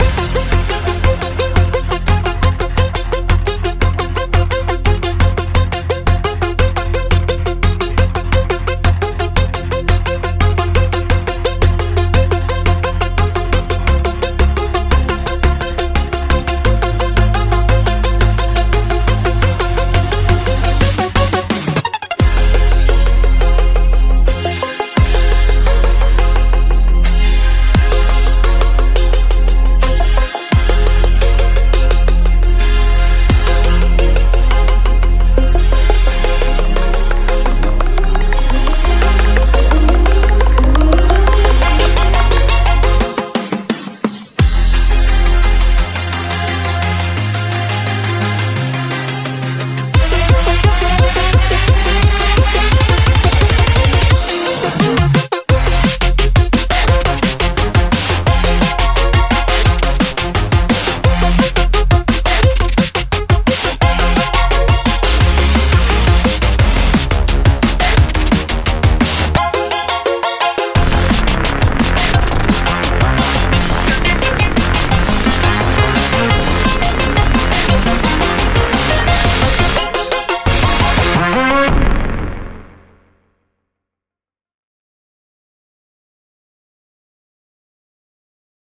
a refined collection of 100 cinematic sci-fi pluck presets